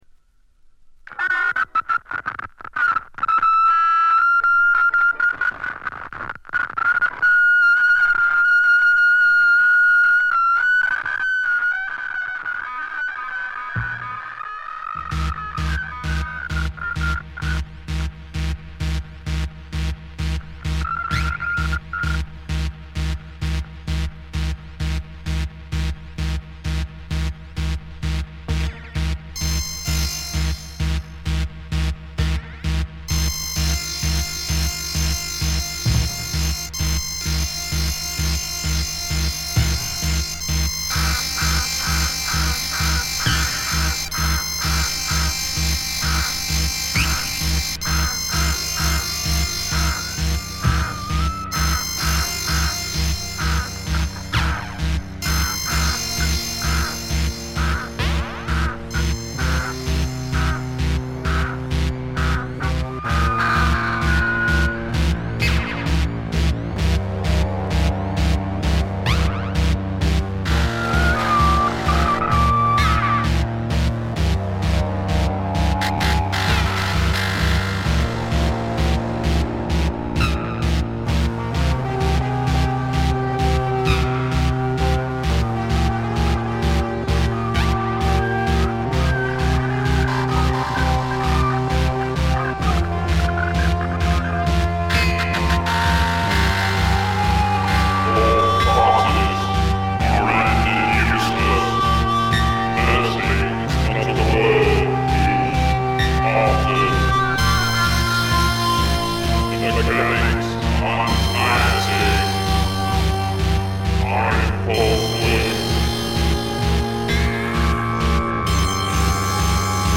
試聴曲は現品からの取り込み音源です。
Electronics, Tape
Drums